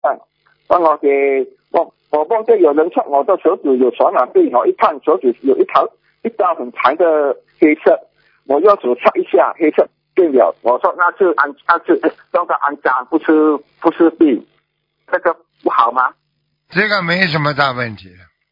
目录：☞ 剪辑电台节目录音_集锦